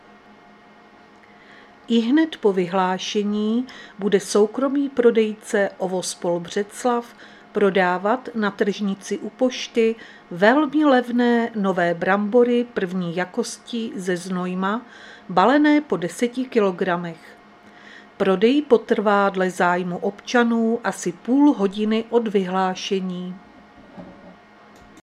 Záznam hlášení místního rozhlasu 18.6.2025
Zařazení: Rozhlas